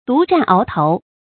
注音：ㄉㄨˊ ㄓㄢˋ ㄠˊ ㄊㄡˊ
讀音讀法：